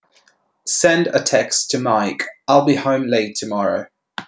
5. 音声分類(会話の音声)
スピーチ用の音声は
speech_16000_hz_mono.wav